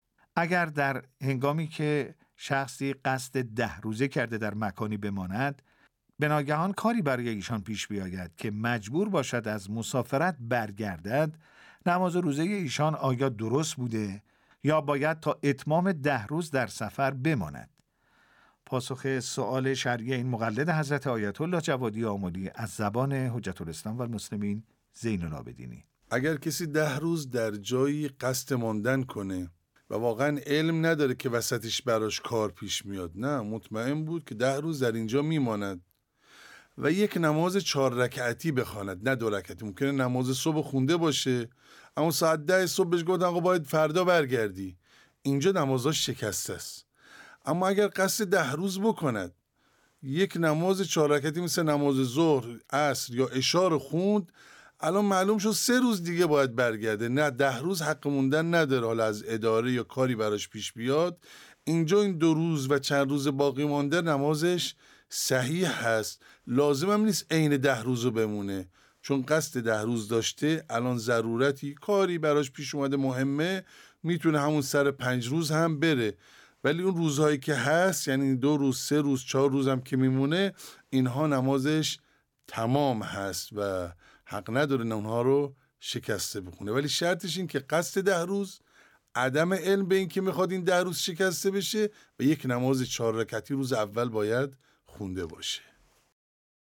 پاسخ نماینده دفتر حضرت آیت الله العظمی جوادی آملی